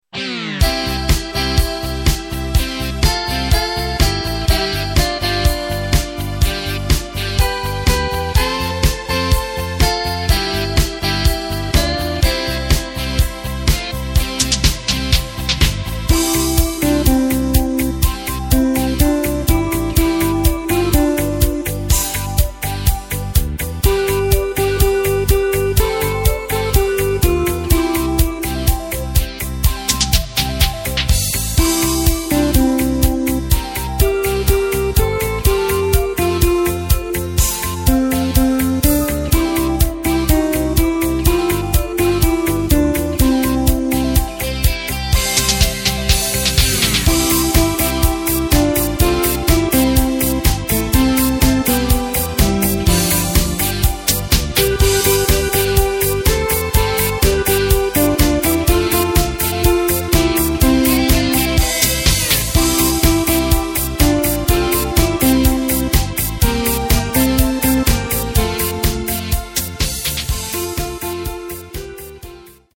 Takt:          4/4
Tempo:         124.00
Tonart:            C
Discofox aus dem Jahr 2019!